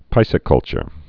(pīsĭ-kŭlchər, pĭsĭ-)